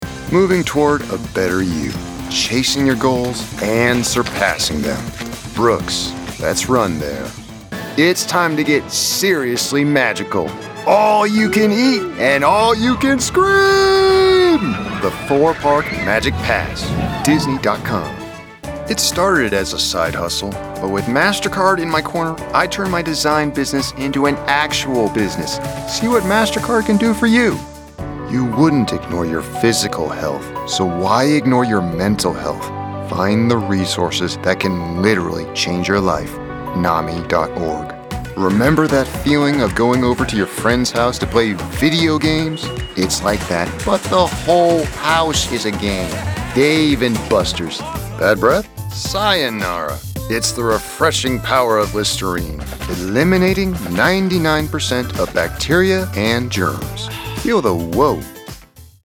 Commercial Demo Reel
English - USA and Canada
Young Adult